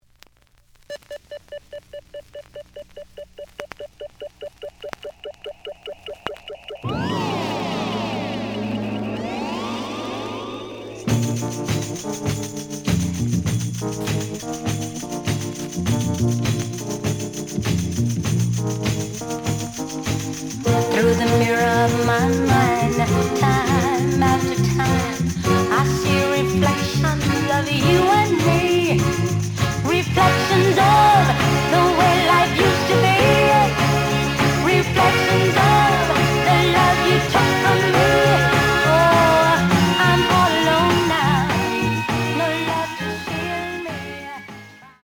The audio sample is recorded from the actual item.
●Genre: Soul, 60's Soul
Looks good, but slight noise on A side.)